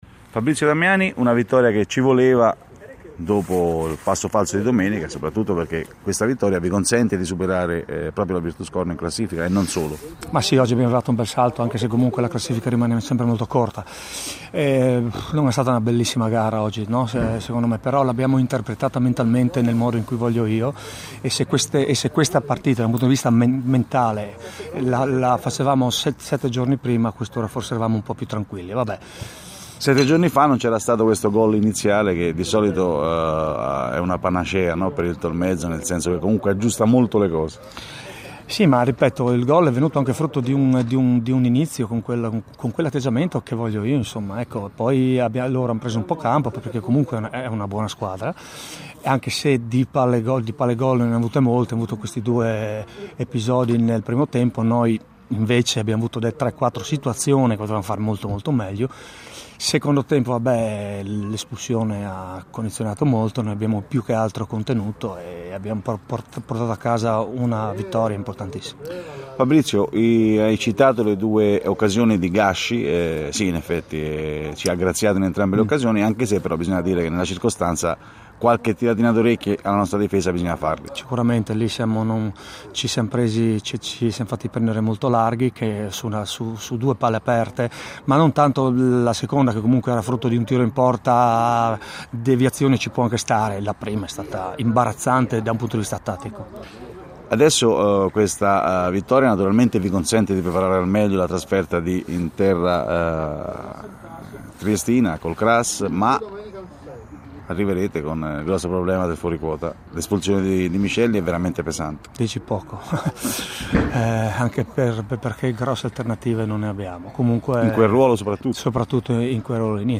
audiointerviste